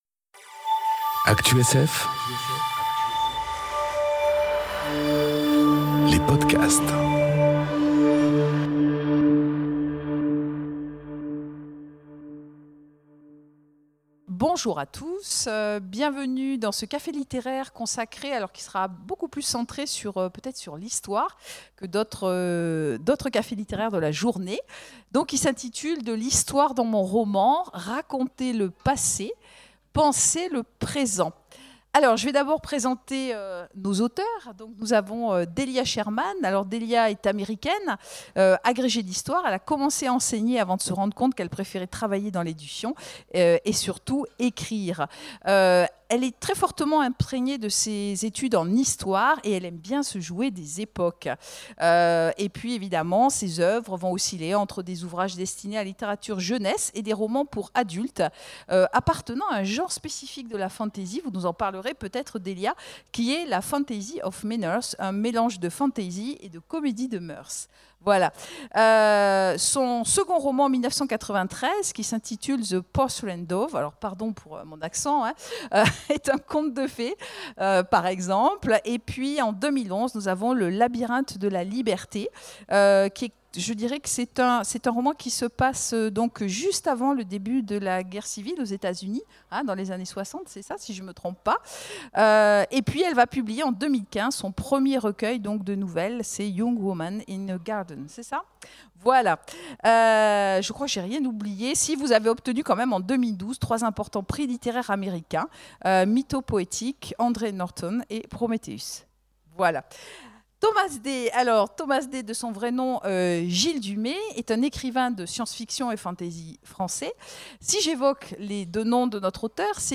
Conférence De l'histoire dans mon roman : raconter le passé, penser le présent enregistrée aux Imaginales 2018